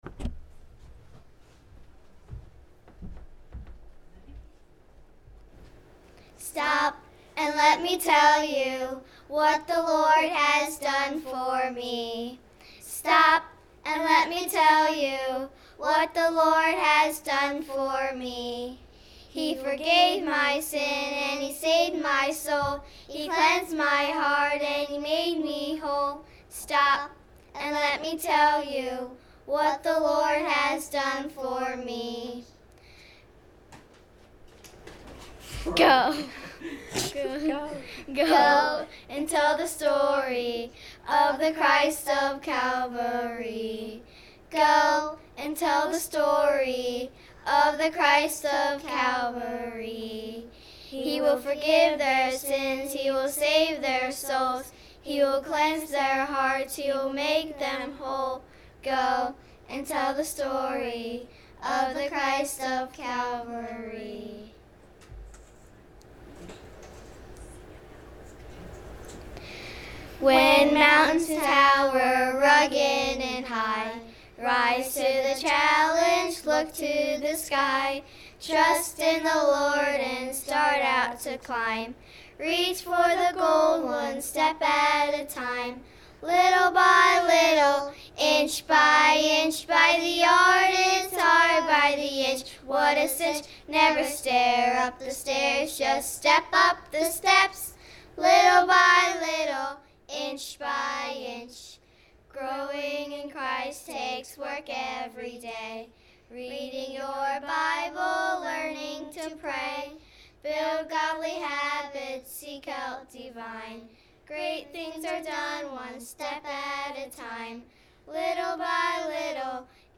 Children’s Trio
August 11, 2019 Children’s Trio Service Type: Sunday Worship | The Children’s Trio did a great job!
Childrens-Trio.mp3